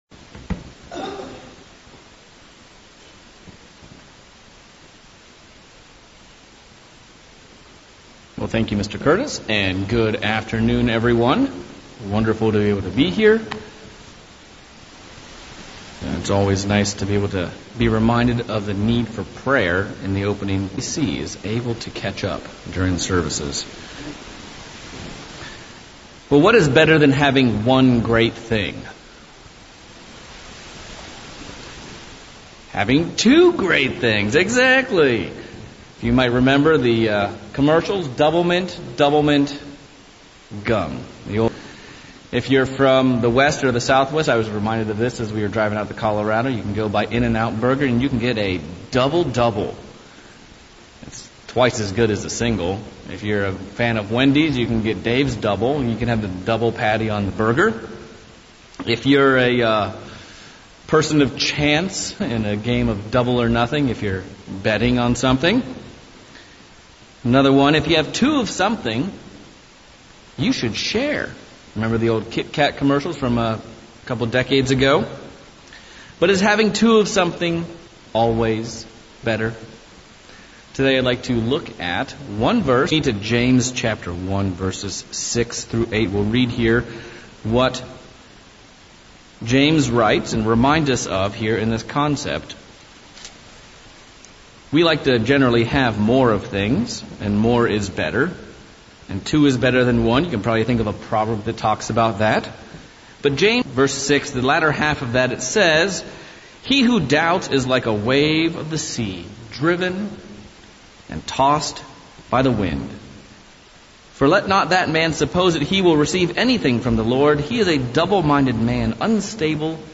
Sermonette covering the subject of double-mindedness as covered in James 1:8. What is Double-mindedness and how can we take steps to avoid it and not be "wishy-washy" spiritually?